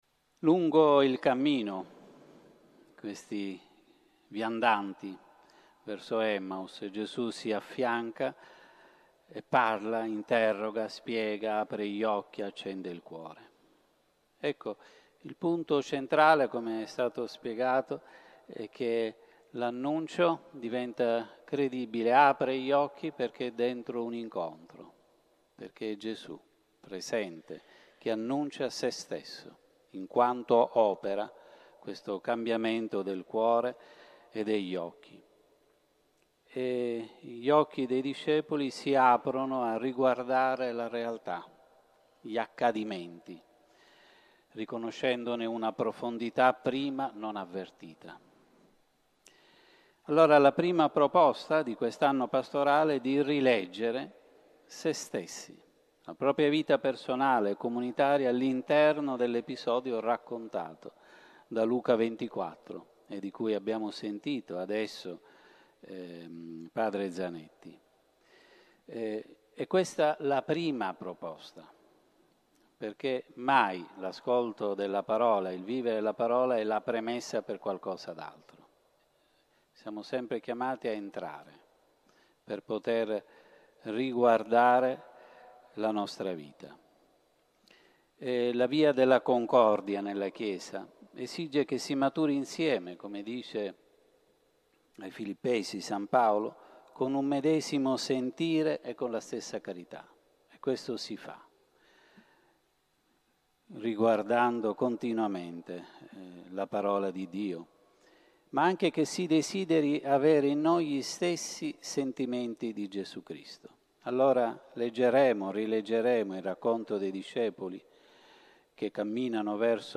Si è svolta nel pomeriggio di venerdì 13 ottobre, presso la Basilica di Nostra Signora di Bonaria in Cagliari, l’assemblea ecclesiale per l’apertura del nuovo anno pastorale diocesano, presieduta dall’arcivescovo monsignor Giuseppe Baturi, che ha goduto di una nutrita partecipazione da parte di rappresentanti delle parrocchie e degli organismi ecclesiali presenti nel territorio.